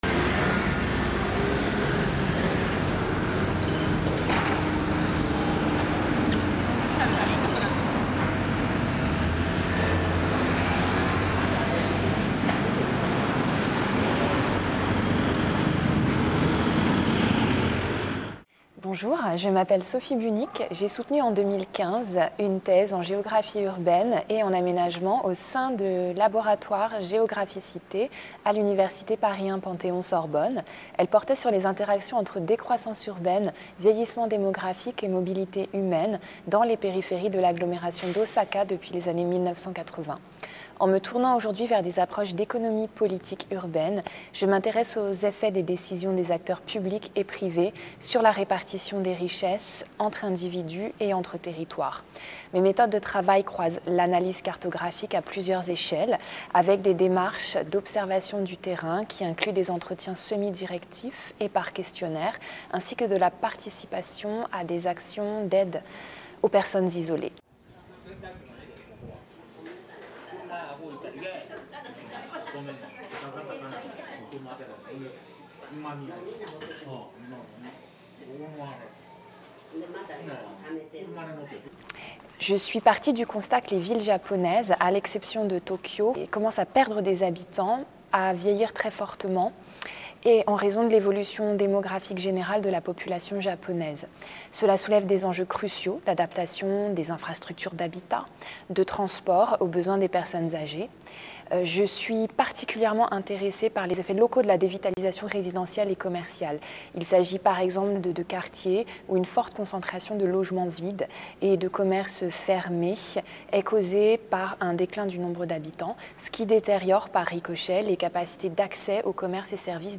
Quelles conséquences pour les espaces périurbains ? Entretien